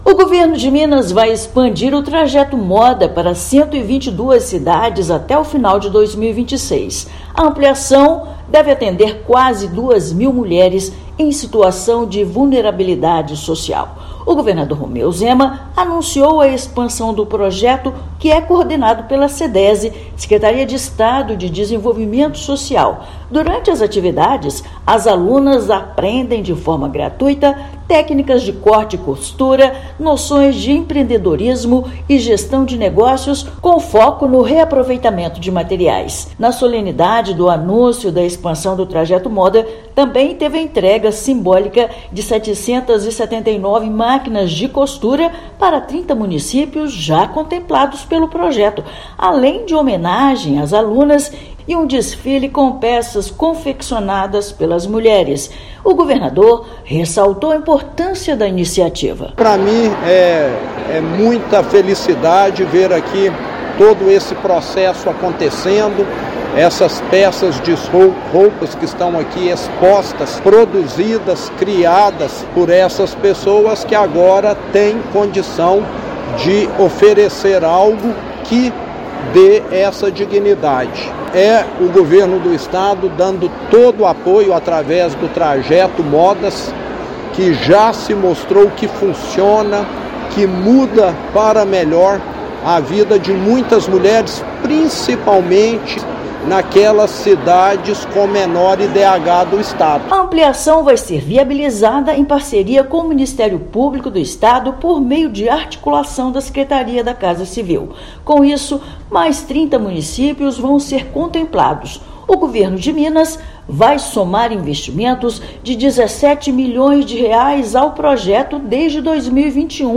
Iniciativa deve capacitar mais de 1,7 mil mulheres em situação de vulnerabilidade social no estado. Ouça matéria de rádio.